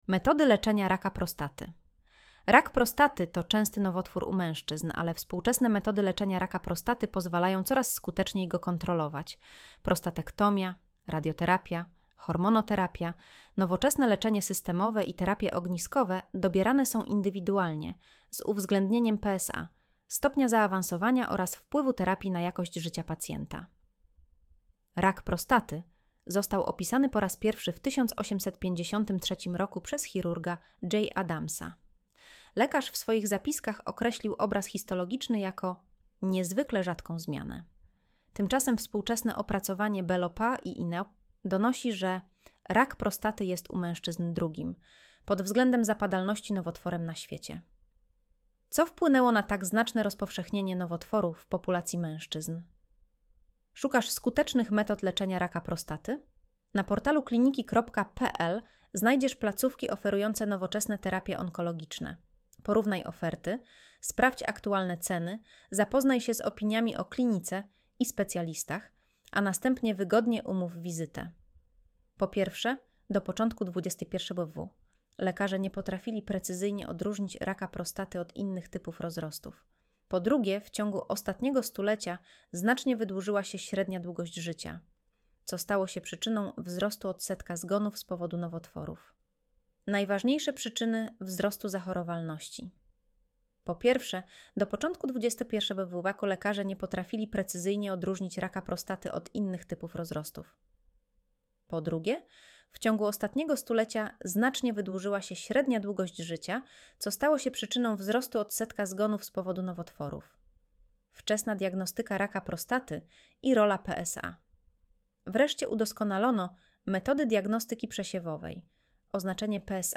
Słuchaj artykułu Audio wygenerowane przez AI, może zawierać błędy